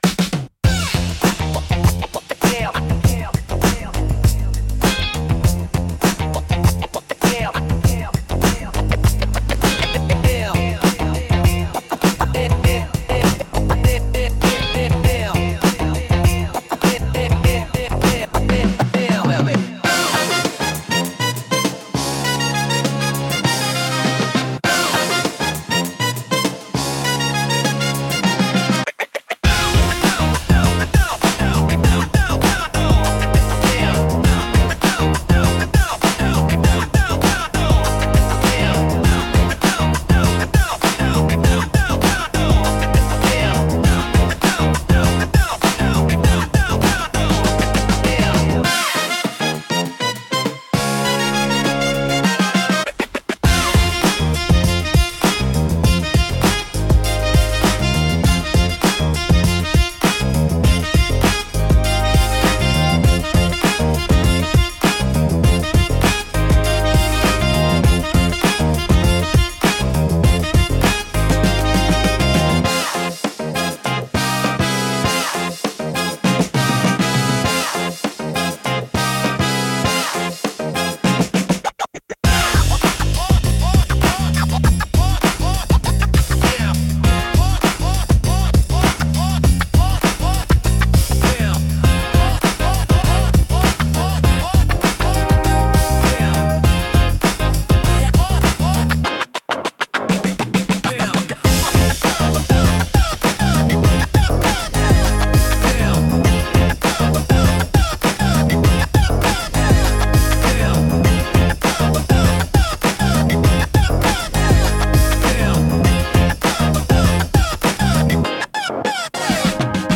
若々しく自由なエネルギーを感じさせ、都会的でポップな空間作りや動画配信の明るい雰囲気づくりに効果的です。